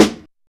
Snare (12).wav